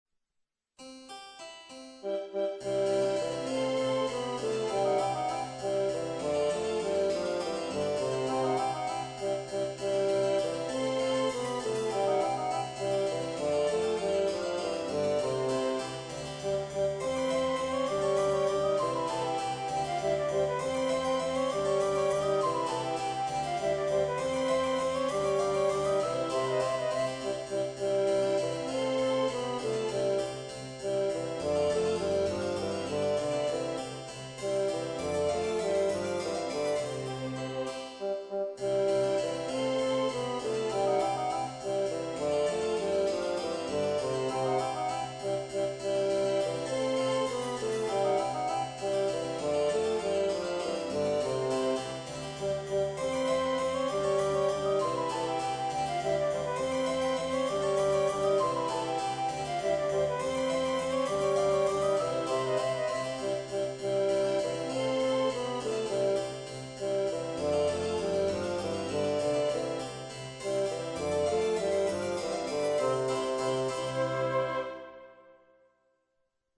E' il finale de Il maestro di cappella, qui proposto per flauto in versione didattica.